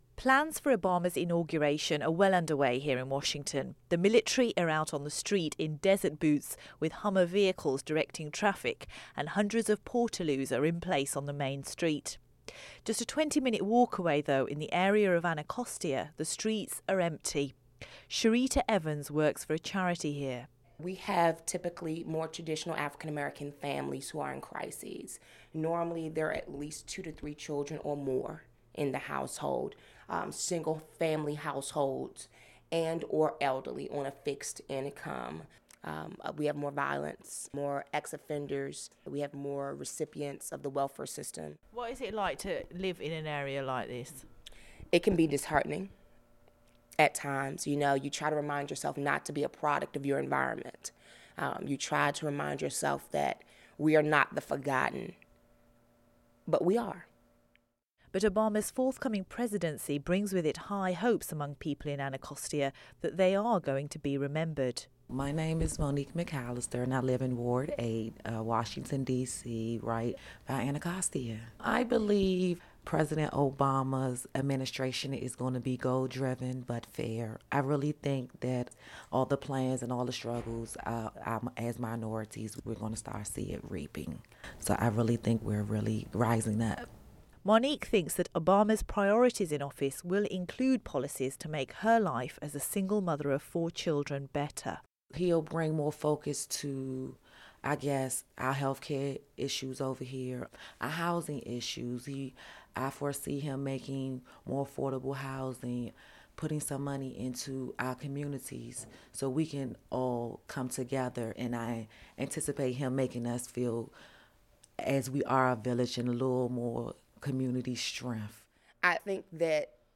reports on Obama's Inauguration as President in January 2009 from the Washington suburb, Anacostia.